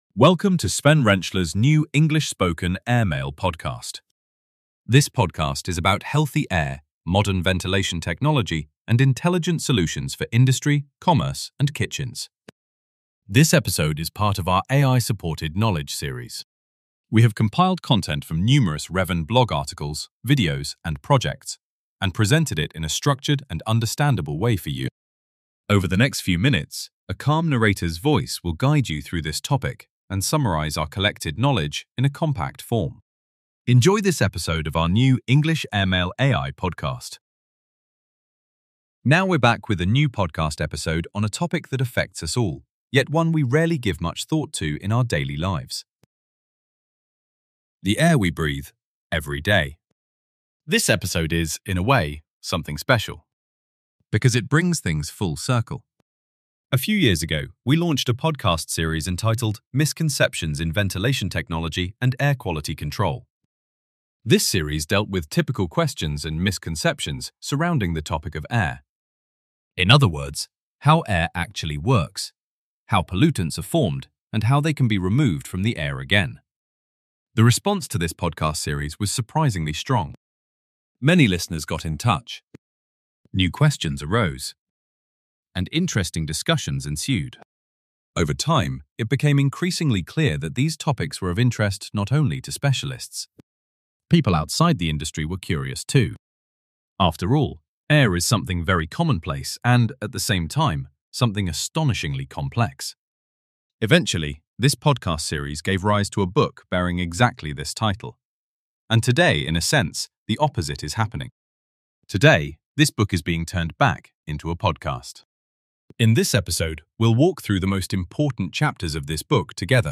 ai-podcast-ventilation-misconceptions.mp3